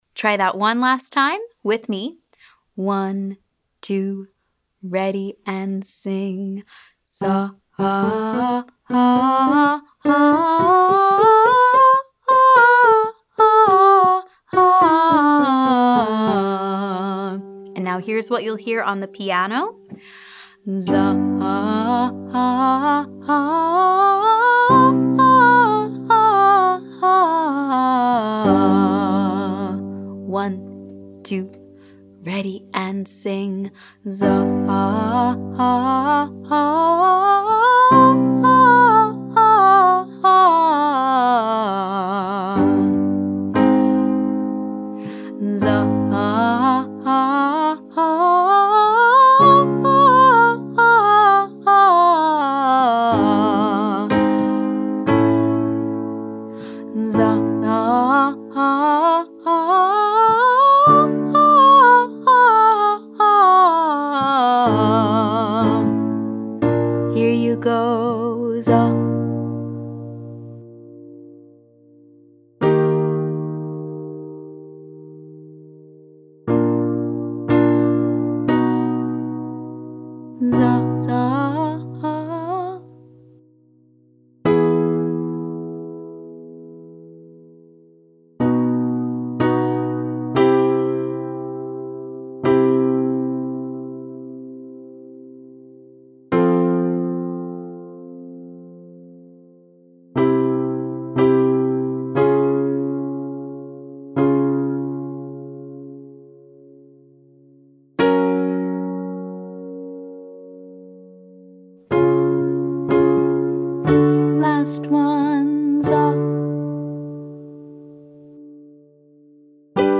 I call an exercise “sweeping” when it covers multiple registers of the voice with one single pattern.
Light mix - fast & bouncy